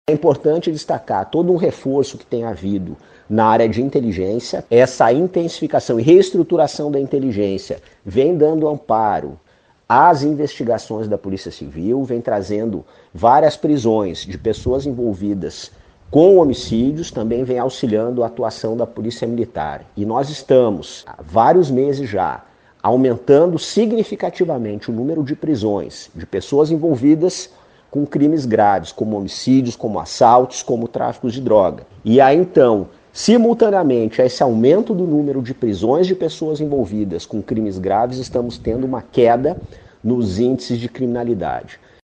O secretário Sandro Caron destaca ainda o investimento em inteligência policial, o que tem garantindo efetividade nas investigações e consequentemente a prisão de pessoas envolvidas em CVLI e outros crimes.